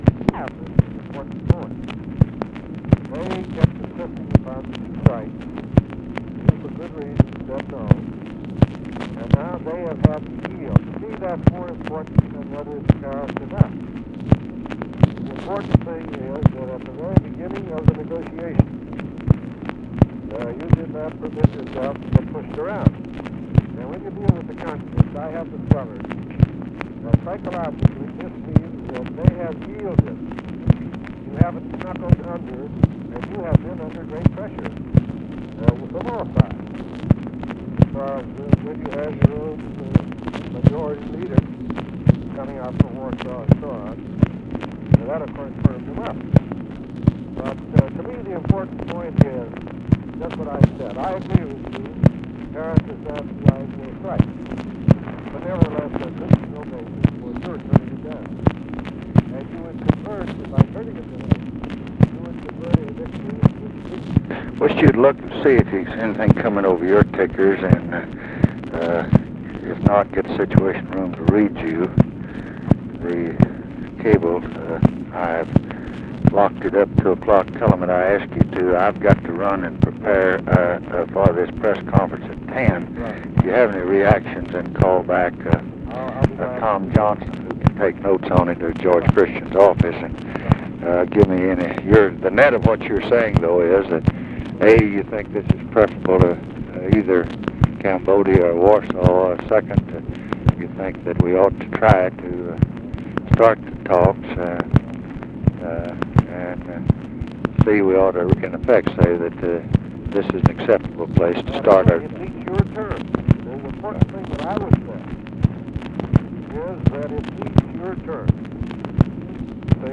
Conversation with ARTHUR GOLDBERG, May 3, 1968
Secret White House Tapes